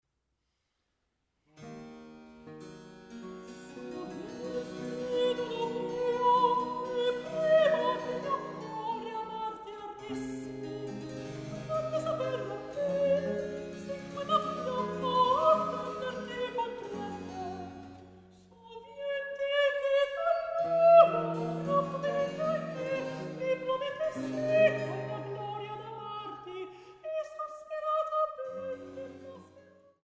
Sopran
Cembalo und musikalische Leitung
Ensemble für Alte Musik